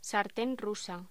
Locución: Sartén rusa
locución
Sonidos: Voz humana